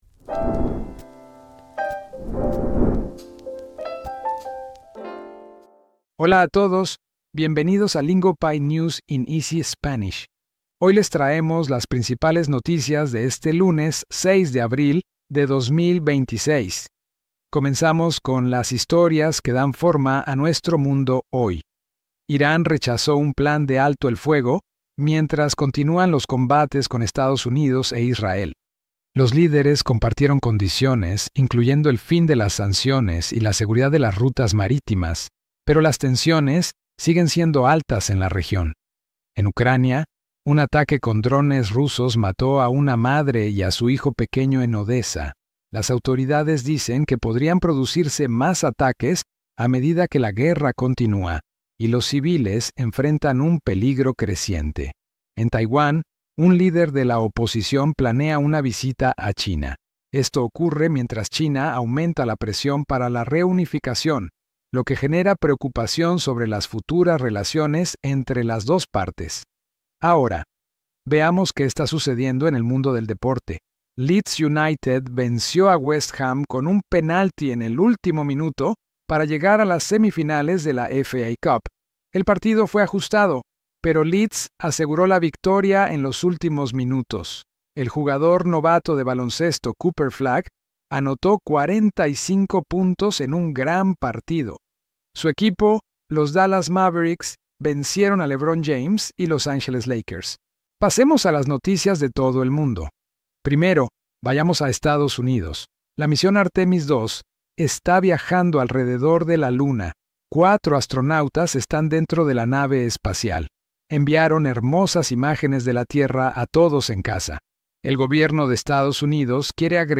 This episode breaks down the biggest stories in clear, beginner-friendly Spanish so you can follow the meaning without getting overwhelmed.